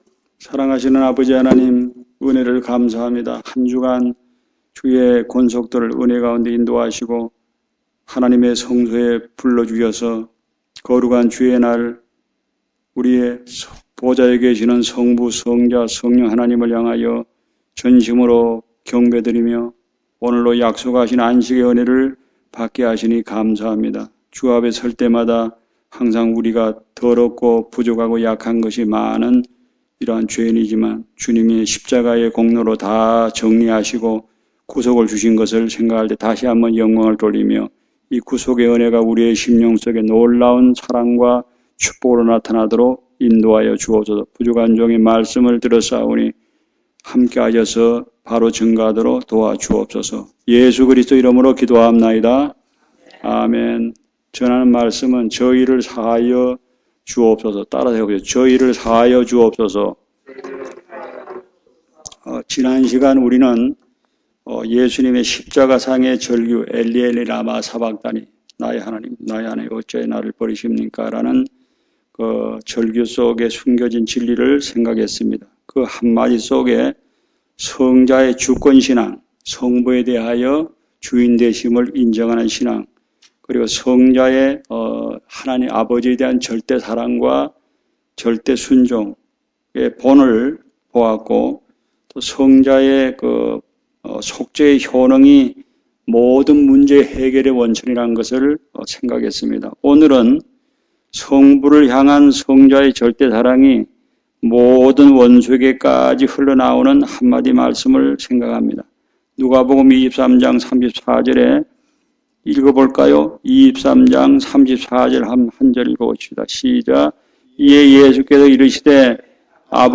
Series: 주일설교